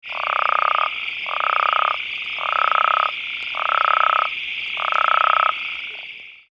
smultiplicatasinglecall.mp3